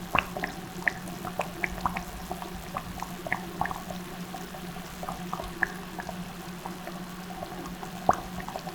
boiling_bubbling_water_gurgle_01.WAV